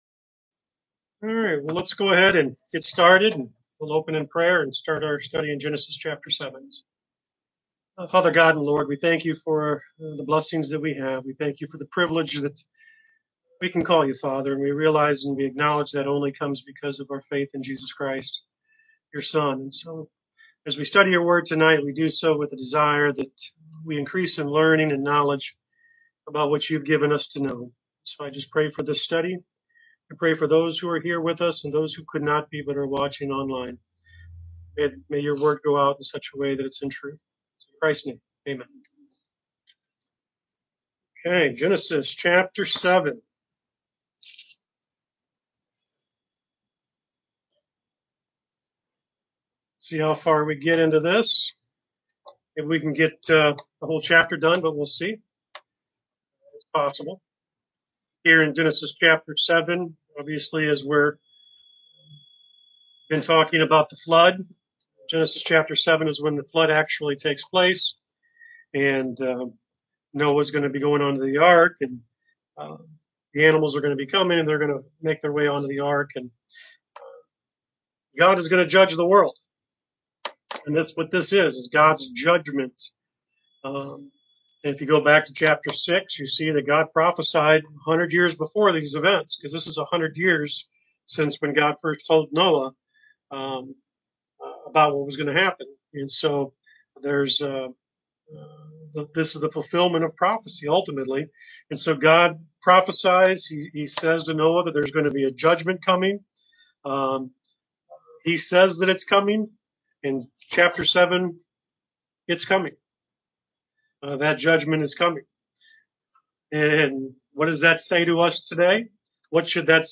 Bible Study: Genesis Ch 7